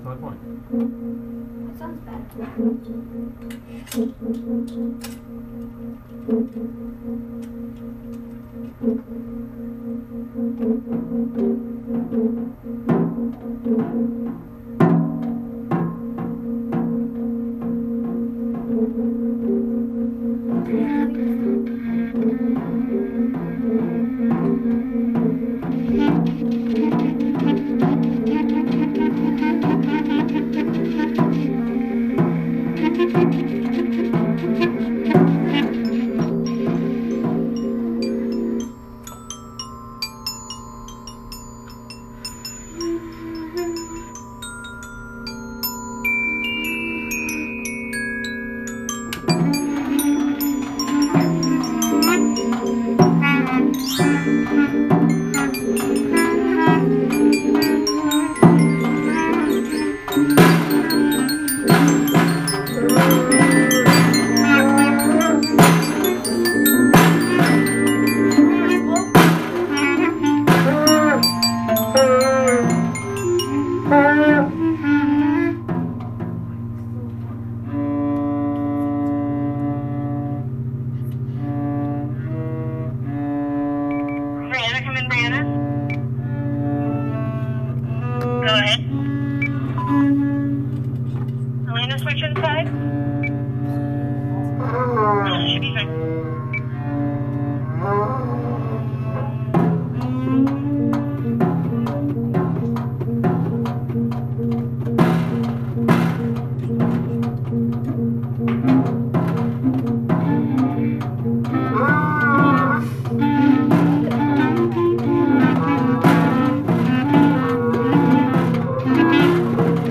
In Dragonfly Band the Dragonflies experimented using different rhythms, creating a beautiful improvisation.
Dragonfly-Rhythmic-Improv.m4a